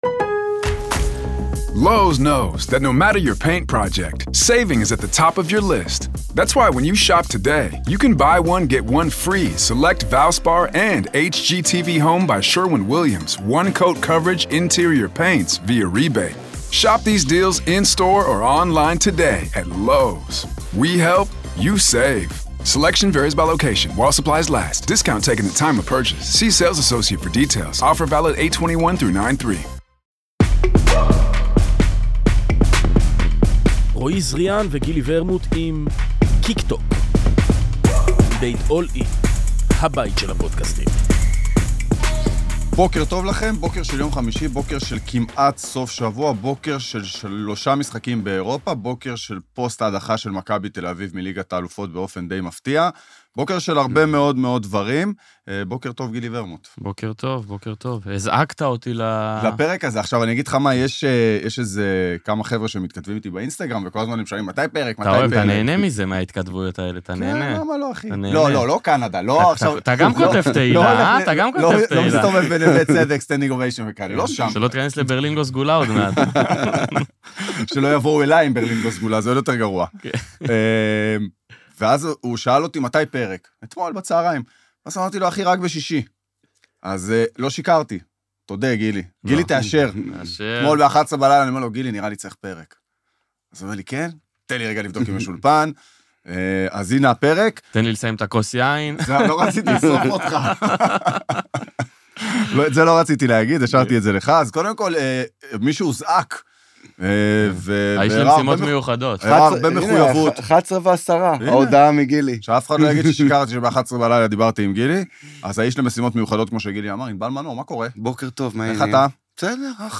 אורח באולפן